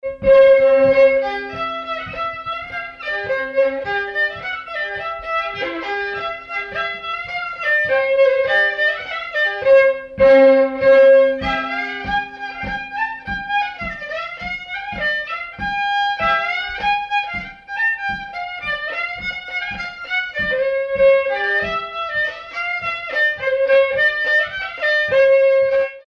danse : marche
circonstance : conscription
Pièce musicale inédite